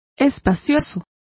Complete with pronunciation of the translation of spacious.